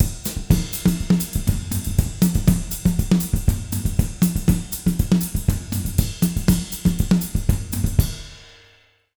240SAMBA04-R.wav